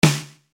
Anhänge Yamaha RX5 Snare.mp3 25,6 KB · Aufrufe: 1.517